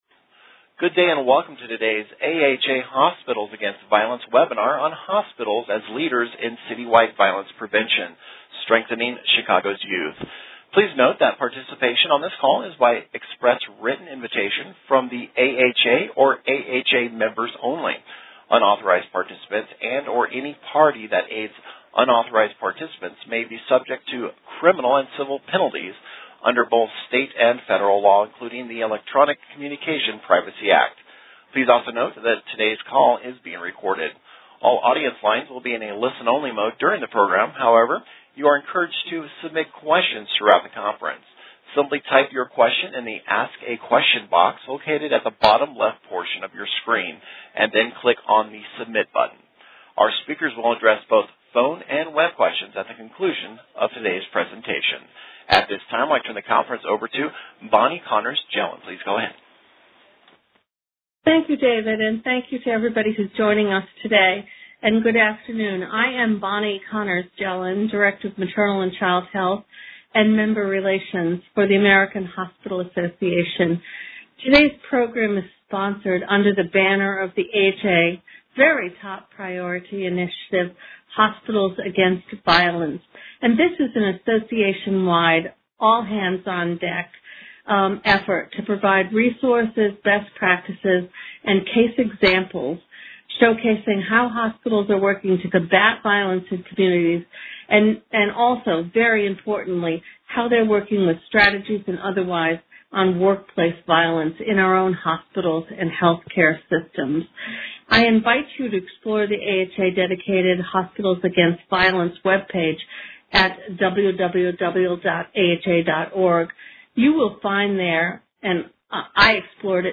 Webinar: Hospitals as Leaders in City-Wide Violence Prevention: Strengthening Chicago's Youth | AHA